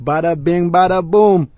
gutterball-3/Gutterball 3/Commentators/Louie/l_baddabingbaddaboom.wav at f3327c52ac3842ff9c4c11f09fb86b6fc7f9f2c5
l_baddabingbaddaboom.wav